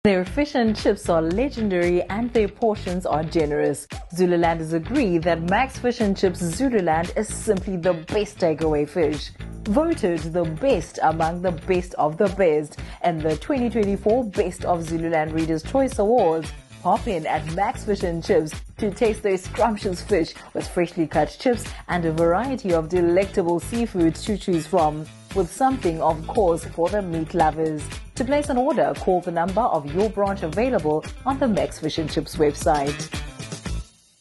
authentic, authoritative, soothing
Macs Fish and Chips advert demo